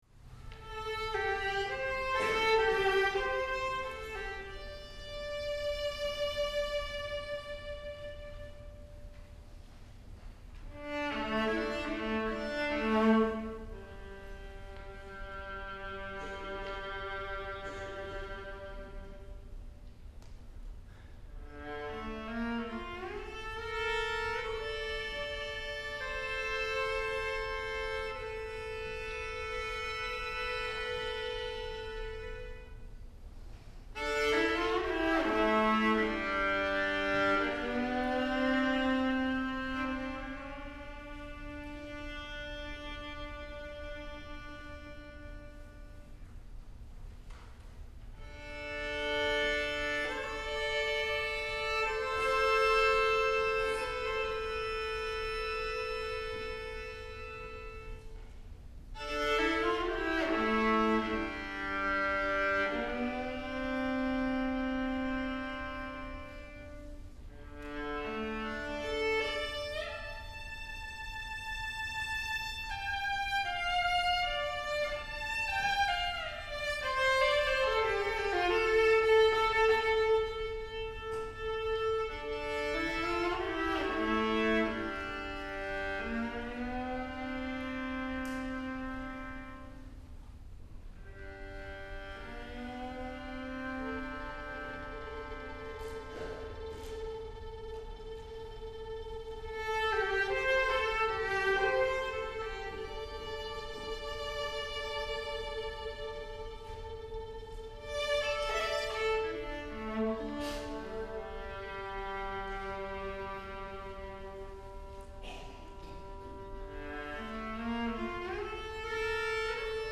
for String Quartet (1988)